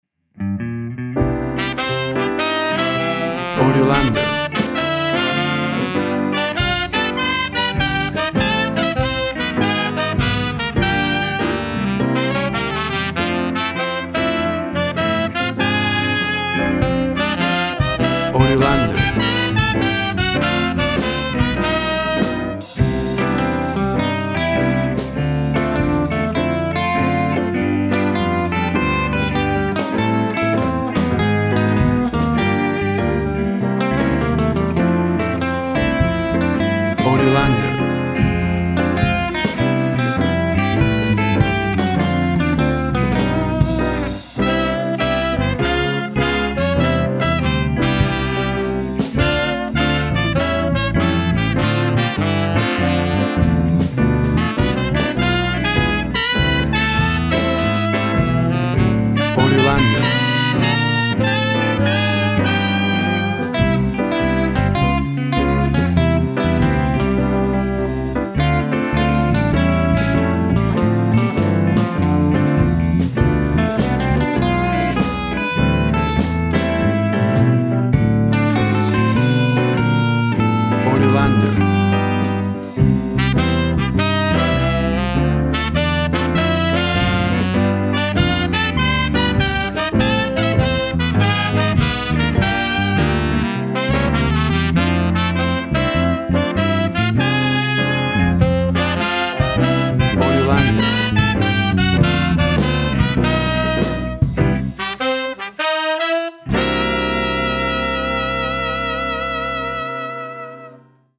Tempo (BPM) 100